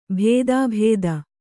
♪ bhēdābhēda